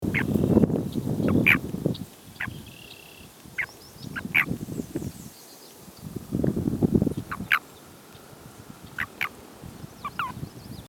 Release Calls
A release call is produced by a male frog or an unreceptive female frog when a frog or other animal (including a human hand) grabs it across the back in the position used for mating or amplexus.
Sound This is a 10 second recording of the release calls of a breeding adult male Canadian Toad.